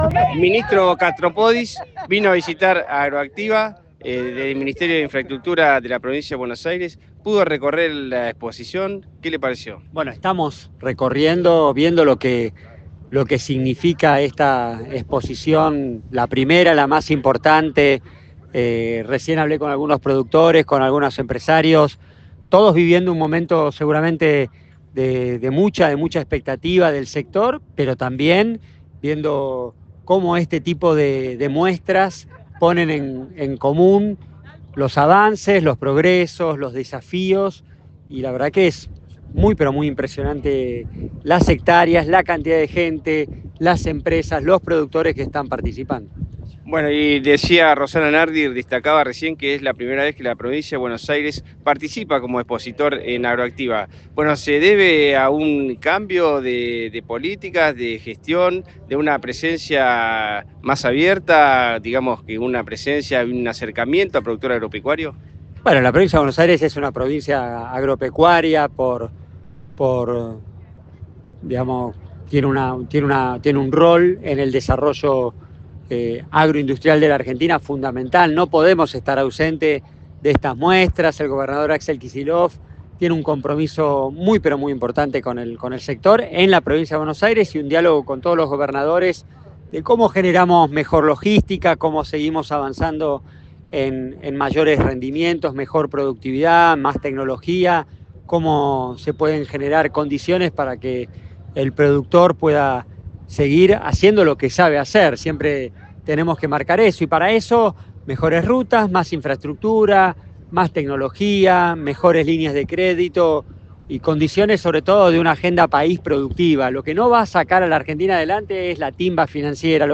Katopodis en AgroActiva:
KATOPODIS.mp3